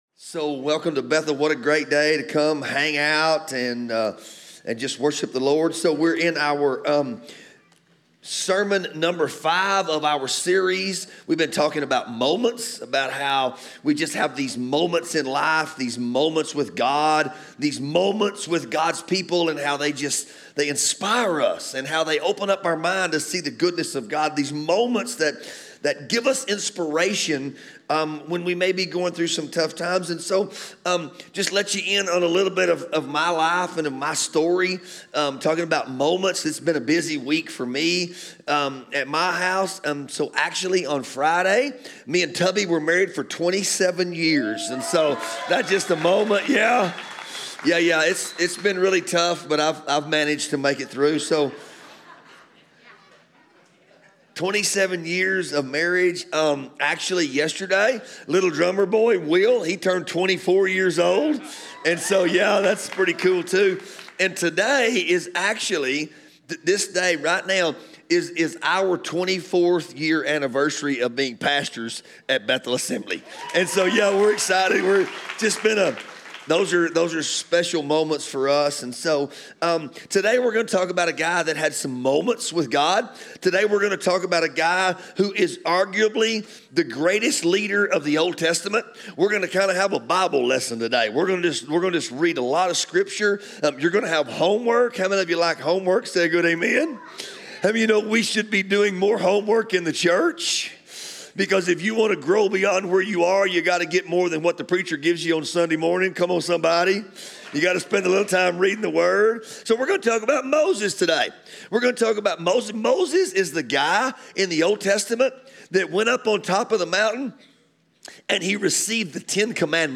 Sermons | Bethel Assembly of God Church